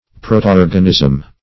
Search Result for " protoorganism" : The Collaborative International Dictionary of English v.0.48: Protoorganism \Pro`to*["o]r"gan*ism\, n. [Proto- + organism.]
protoorganism.mp3